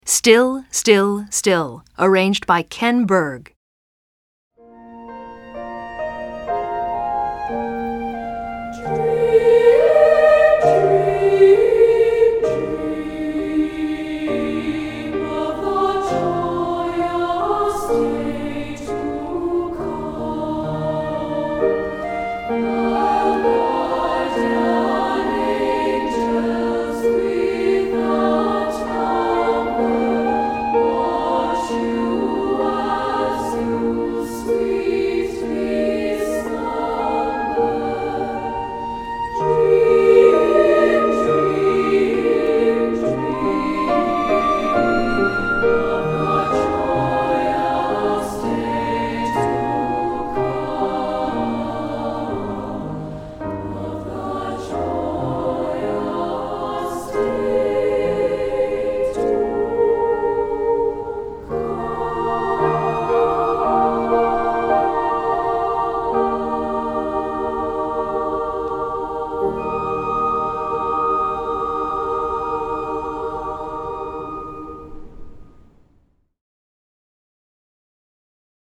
Voicing: SSA